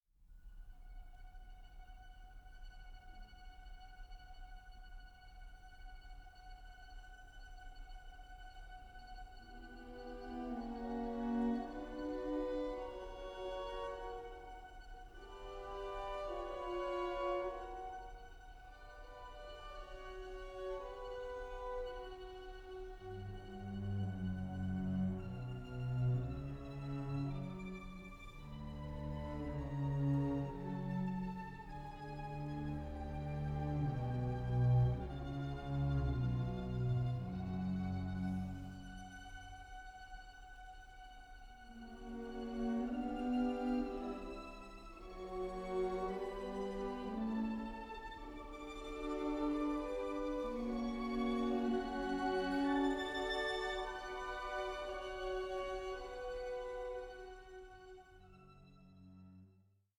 ENERGETIC AND ADVENTUROUS
string ensemble